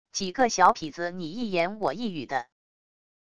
几个小痞子你一言我一语的wav音频